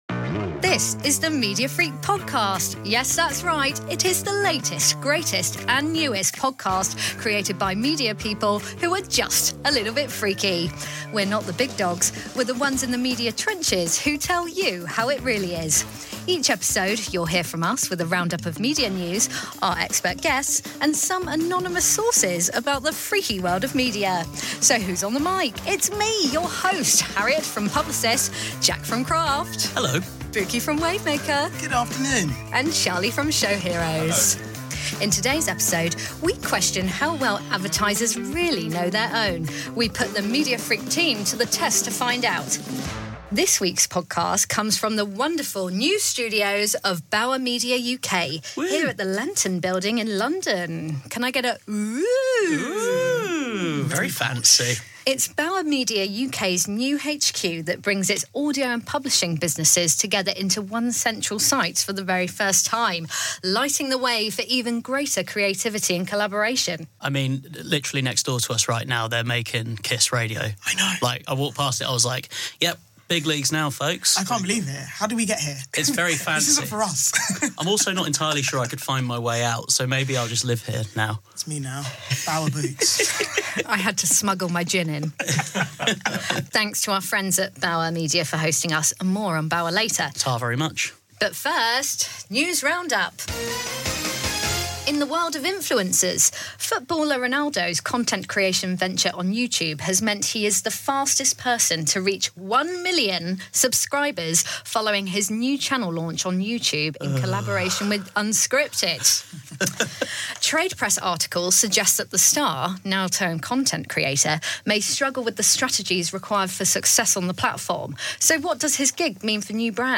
This is the media podcast presented by a team from across the industry. Each episode will feature news and discussion from across the business.